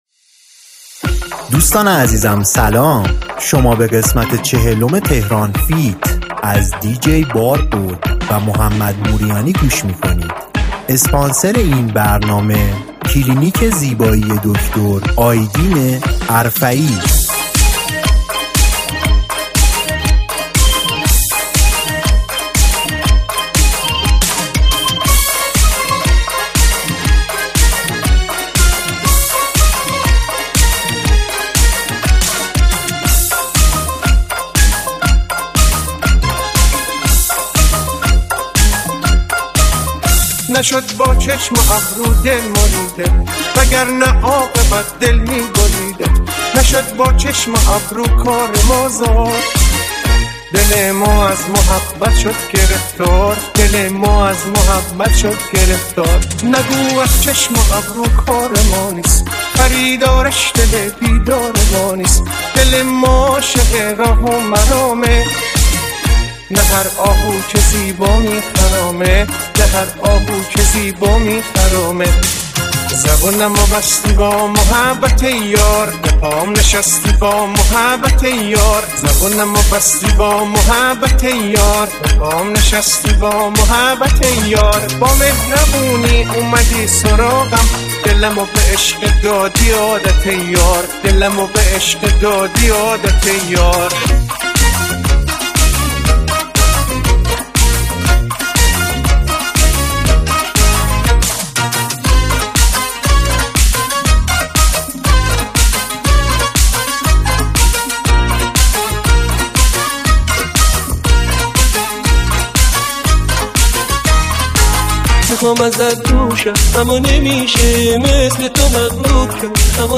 ریمیکس پرانرژی